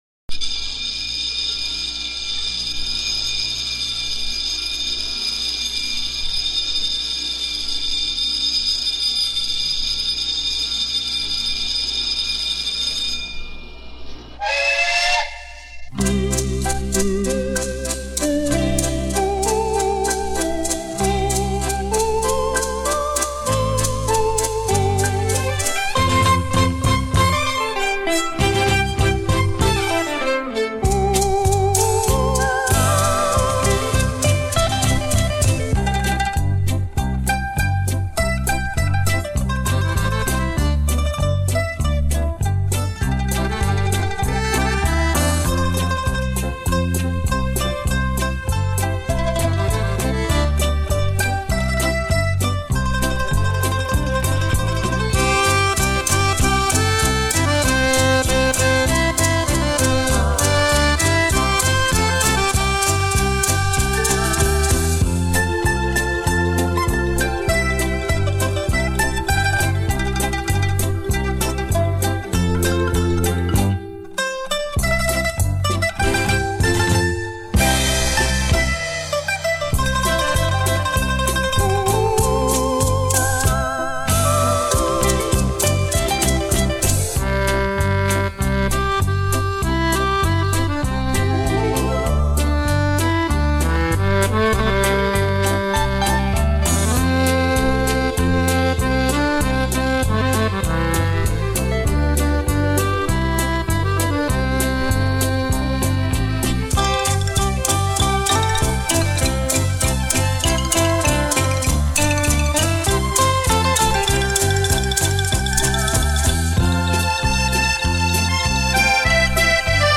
曼陀林、手风琴、电吉他、小提琴